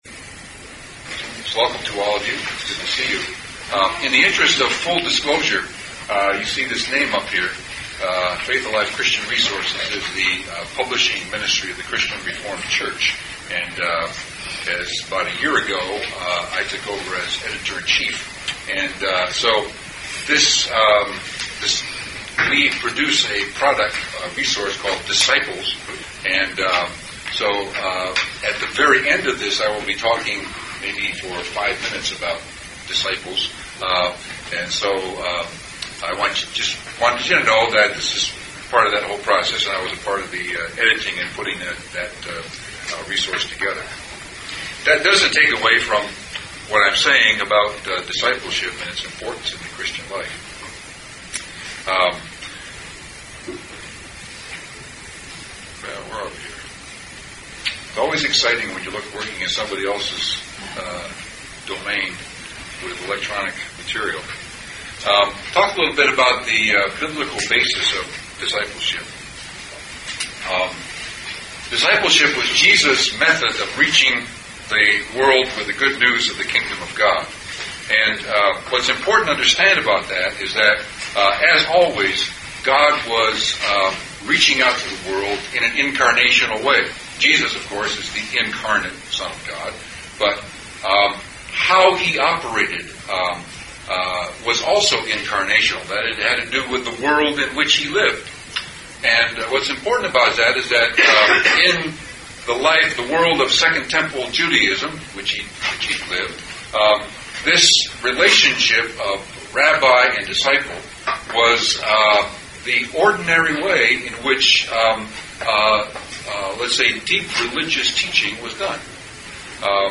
Presented at the 2008 Calvin Symposium on Worship.